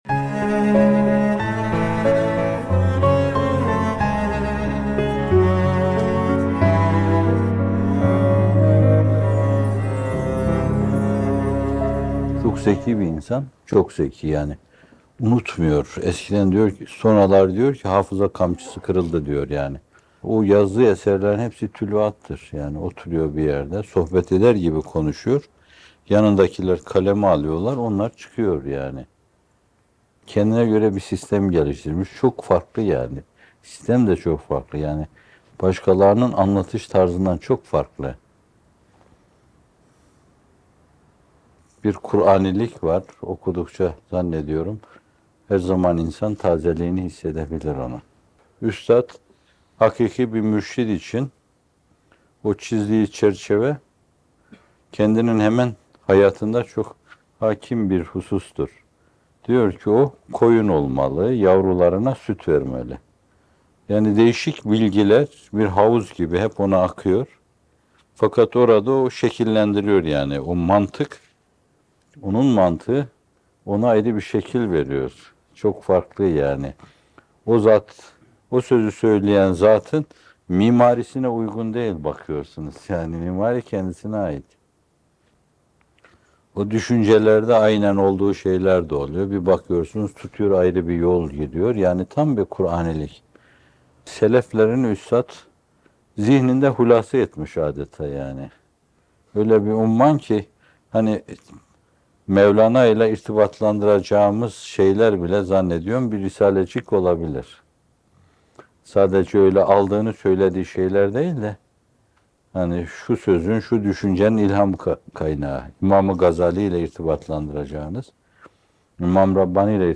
Risale-i Nur’u Anlamak: Üslup, Derinlik ve Hakikat - Fethullah Gülen Hocaefendi'nin Sohbetleri